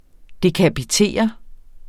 Udtale [ dekabiˈteˀʌ ]